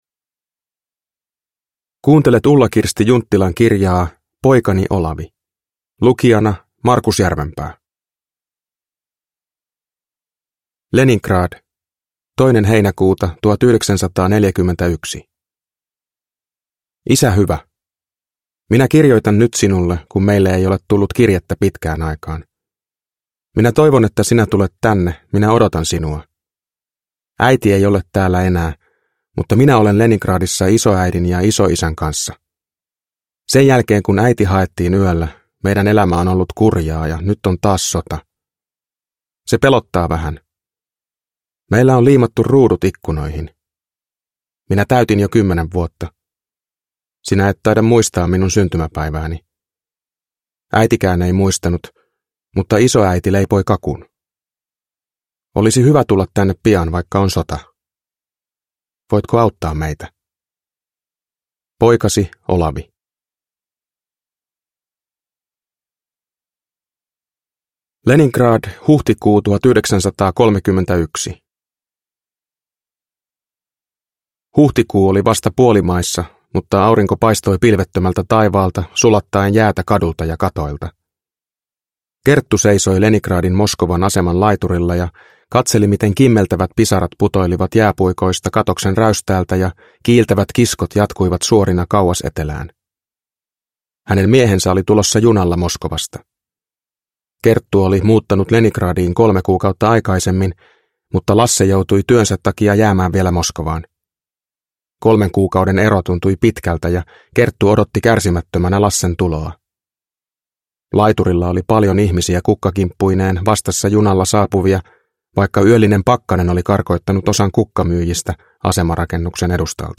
Poikani Olavi (ljudbok) av Ulla-Kirsti Junttila